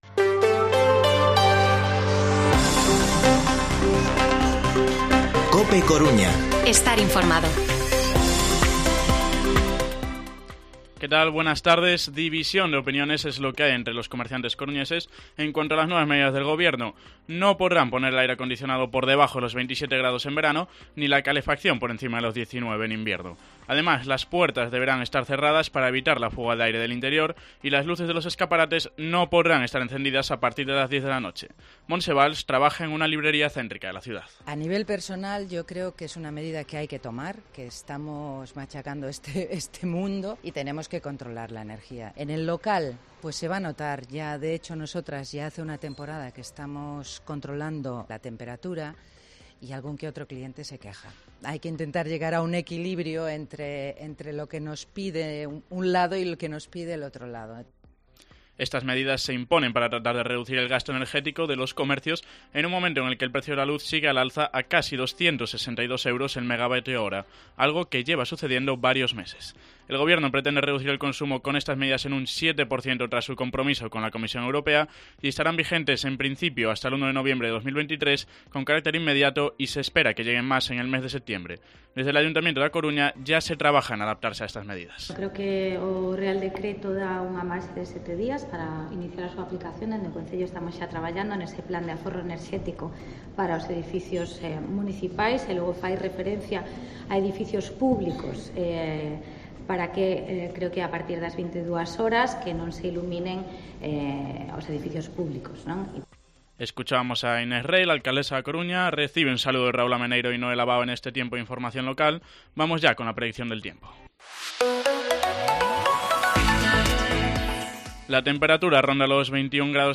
Informativo Mediodía COPE Coruña martes, 2 de agosto de 2022 14:20-14:30